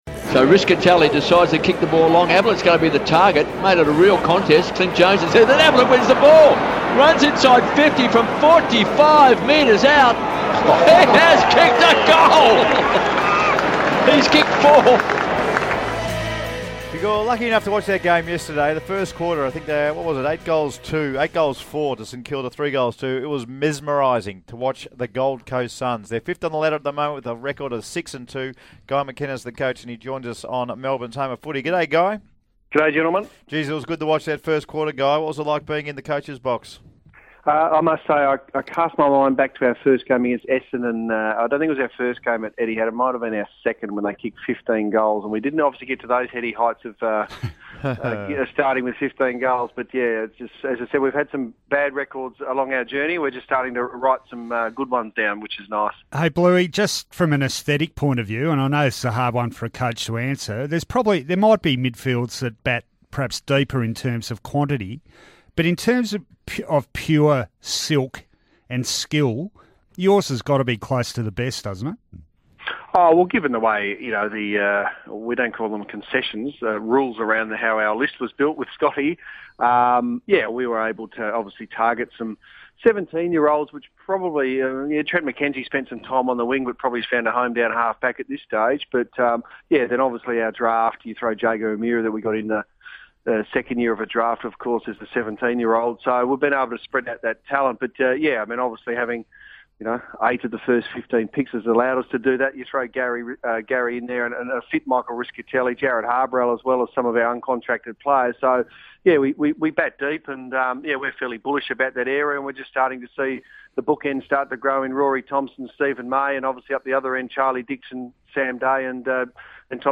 Gold Coast coach Guy McKenna speaks to The Run Home after the GC SUNS' big win against St Kilda.